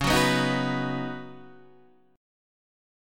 C# 9th Suspended 4th